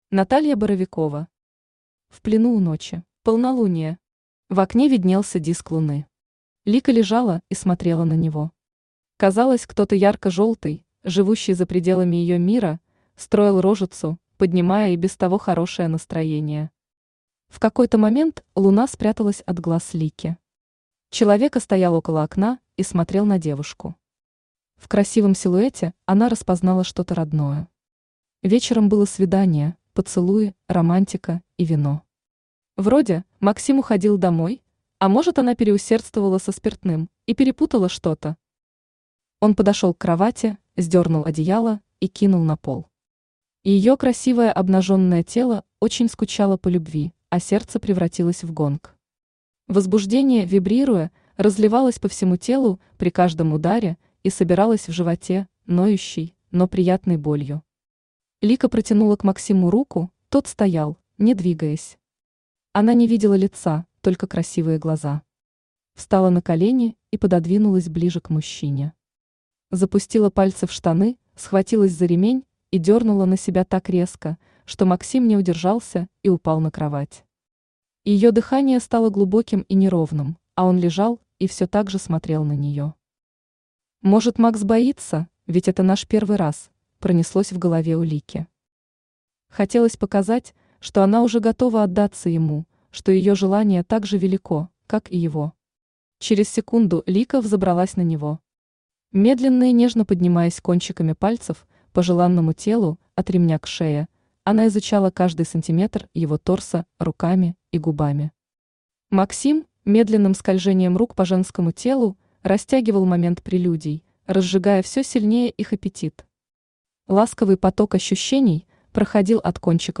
Аудиокнига В плену у ночи | Библиотека аудиокниг
Aудиокнига В плену у ночи Автор Наталья Боровикова Читает аудиокнигу Авточтец ЛитРес.